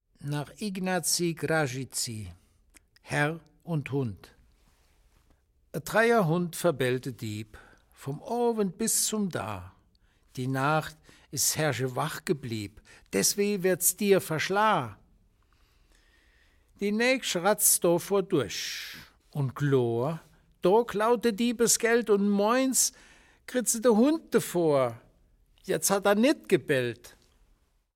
Eine Auswahl an Gedichten von Heinrich Kraus, gelesen